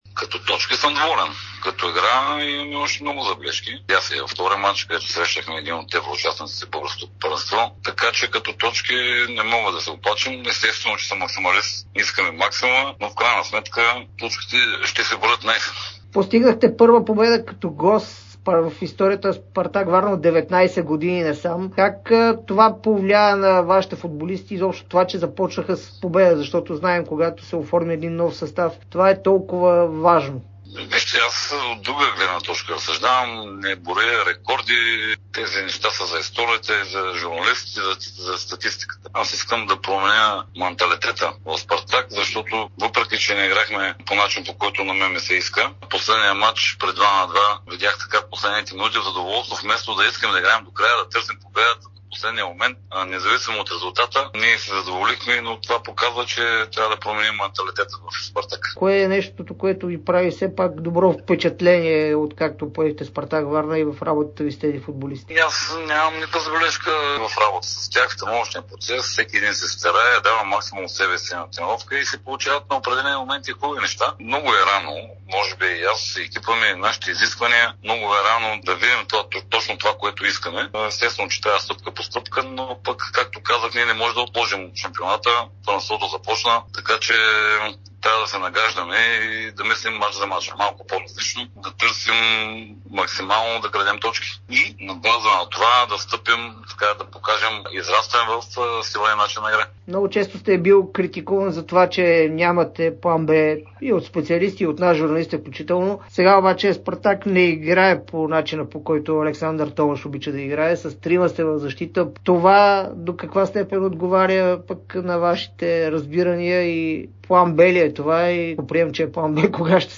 интервю пред Дарик радио и dsport